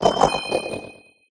indeedee_ambient.ogg